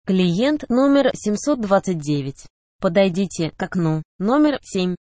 Звуки электронной очереди
На этой странице собраны звуки электронной очереди — знакомые сигналы, голосовые объявления и фоновый шум.